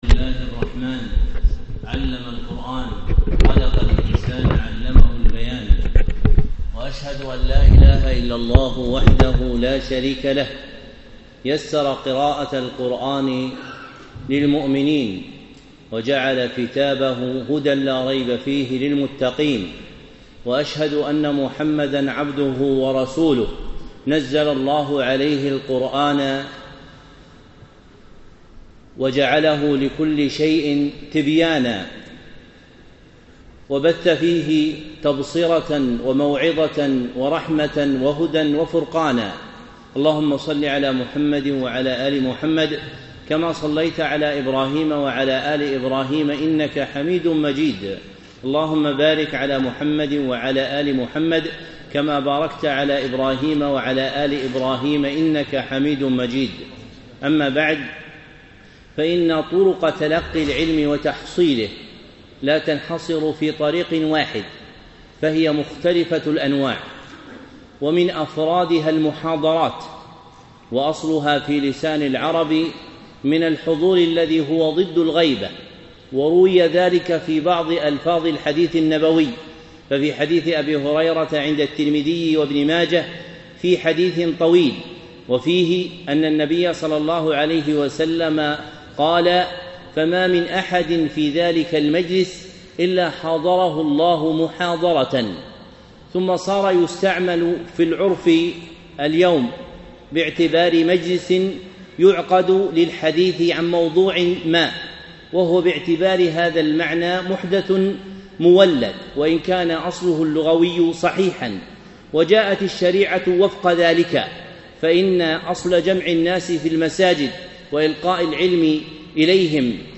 محاضرة - سؤالات البيان في علوم القرآن